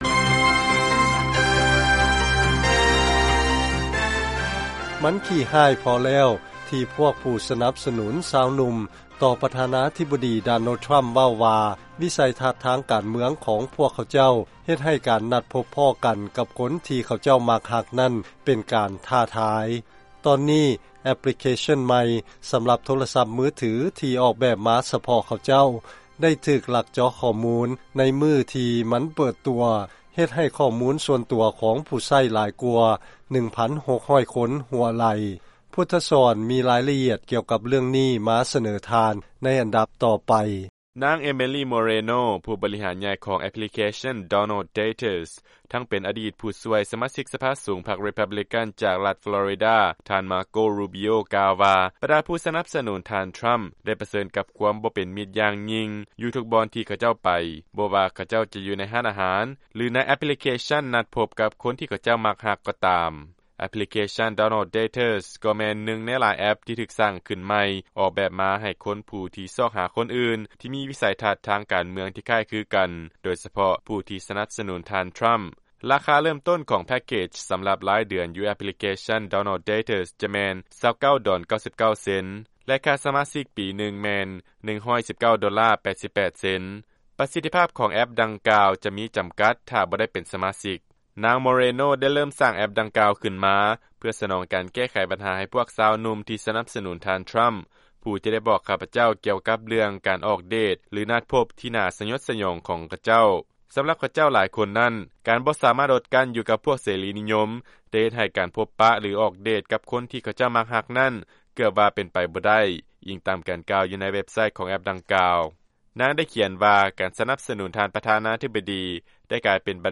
ຟັງລາຍງານ ແອັບພລິເຄຊັນ ນັດພົບຄູ່ຮັກ ຂອງຜູ້ສະໜັບສະໜູນ ທ່ານ ທຣຳ ຖືກເຈາະຂໍ້ມູນບໍ່ດົນຫຼັງການເປີດຕົວ